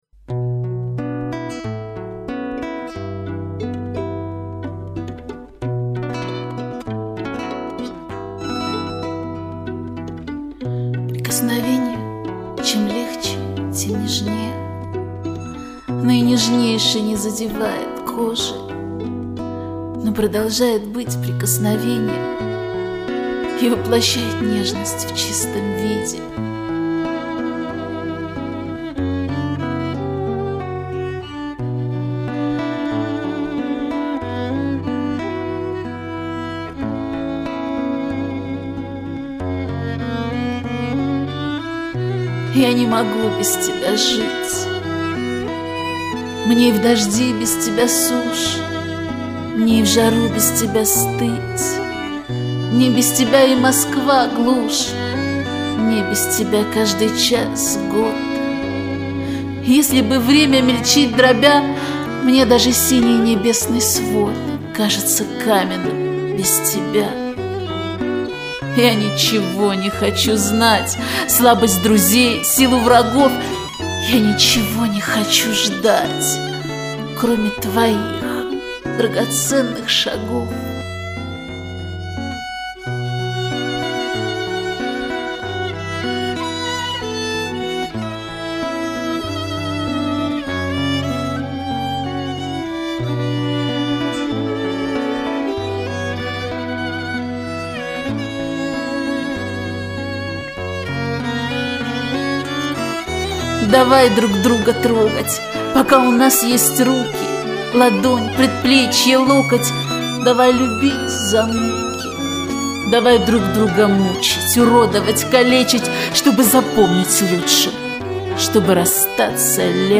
Жду..жду тоже декламацию :) Комментарий соперника: Надеюсь, моя декламация соответствует условиям .